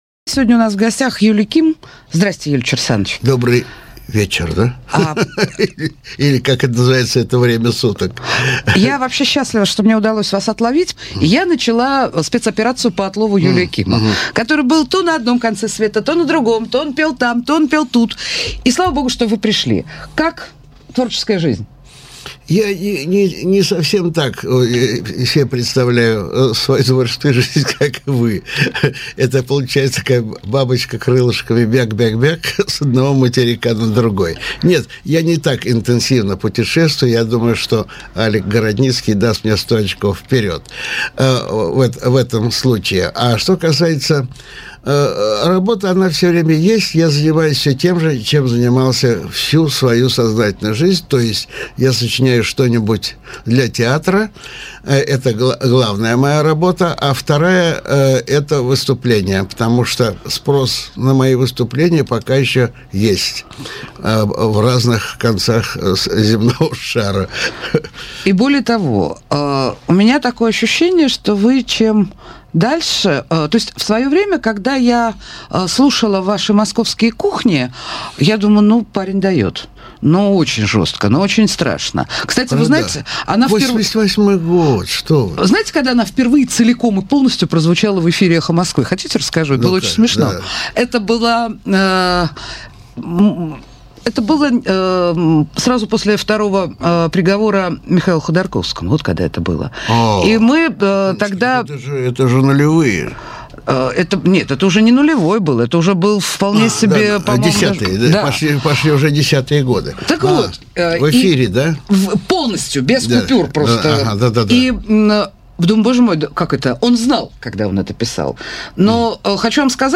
Жанр: Авторская песня